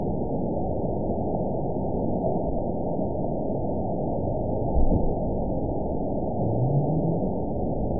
event 922894 date 05/01/25 time 21:00:40 GMT (1 month, 2 weeks ago) score 9.31 location TSS-AB02 detected by nrw target species NRW annotations +NRW Spectrogram: Frequency (kHz) vs. Time (s) audio not available .wav